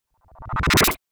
pbs - short alien [ Transition].wav